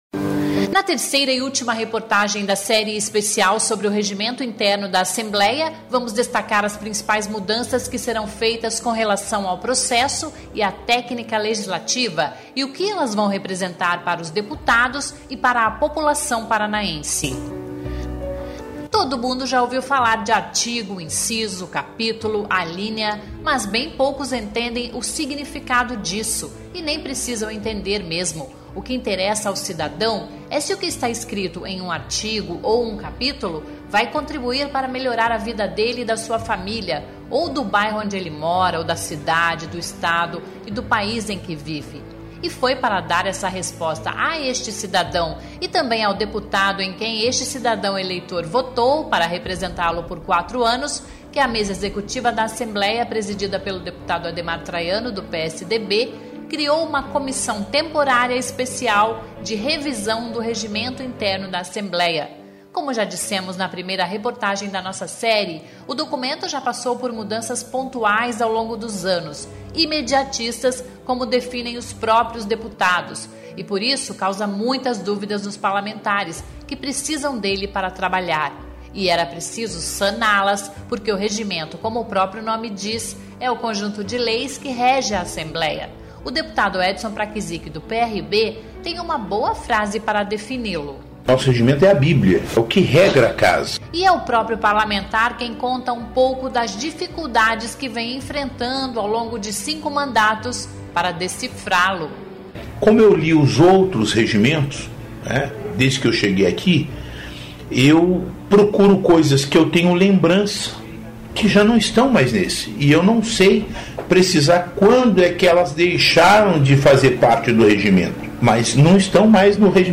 Terceira reportagem da série especial sobre as mudanças no Regimento Interno da Assembleia mostra o que os parlamentares da Comissão de Revisão esperam do novo texto